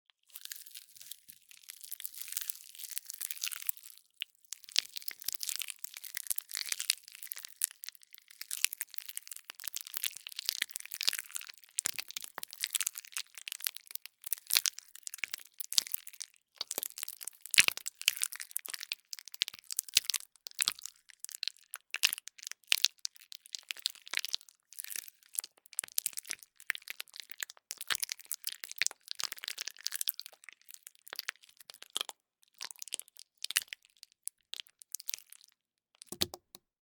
Звук жевания банана ртом (чем еще если не ртом) (00:08)